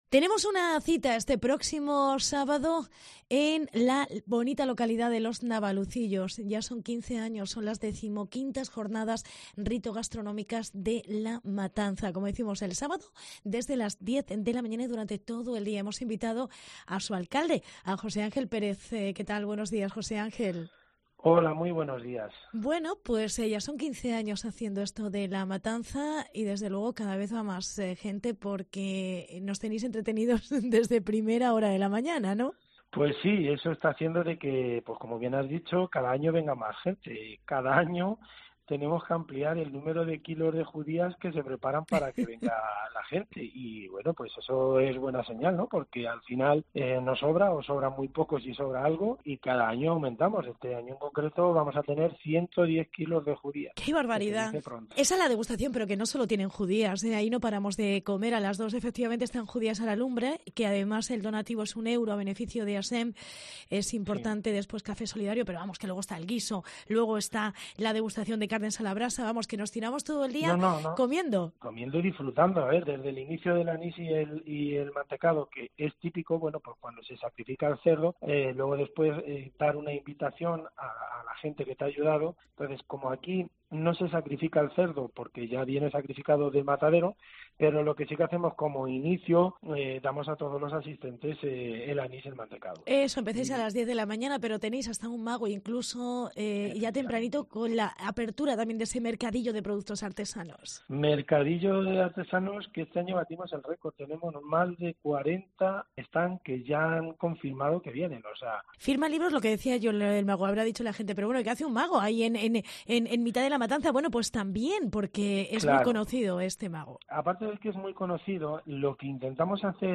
Entrevista José Ángel Pérez. Alcalde de los Navalucillos